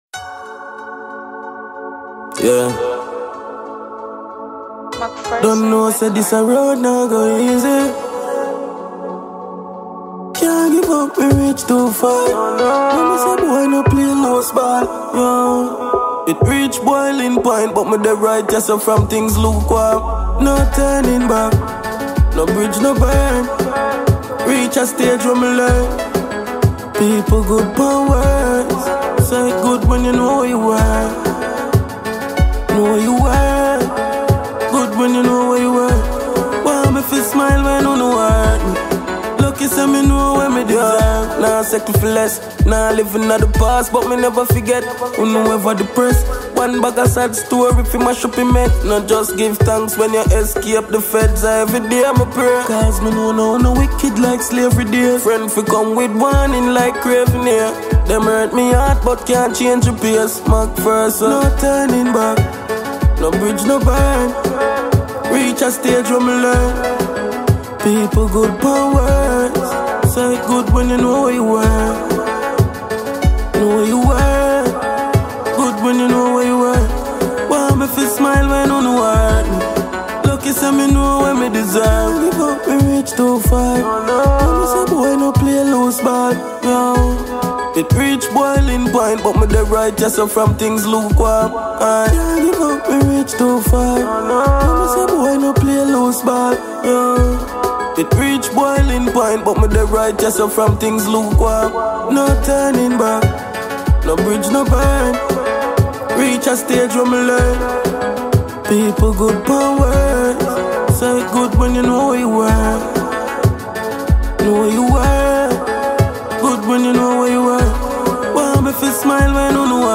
Jamaican award-winning dancehall act